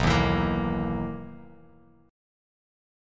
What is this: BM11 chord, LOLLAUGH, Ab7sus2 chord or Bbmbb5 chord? BM11 chord